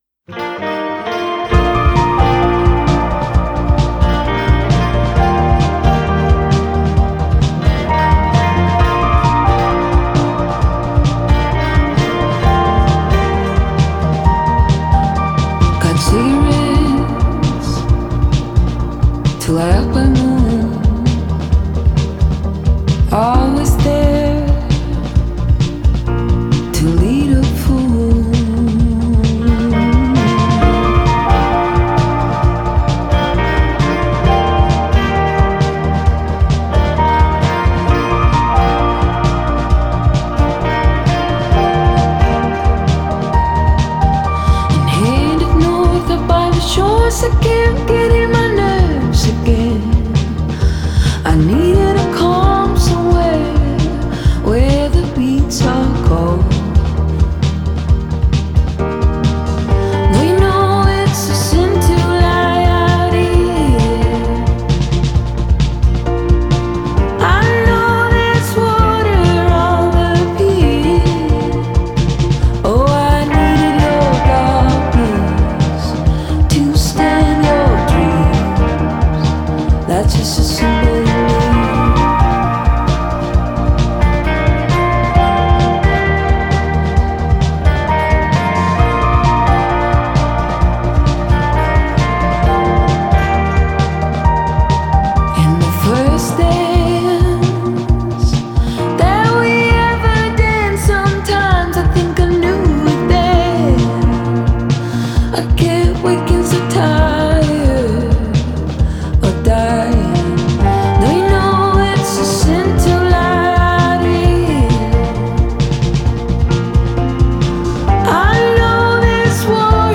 Genre: Pop Folk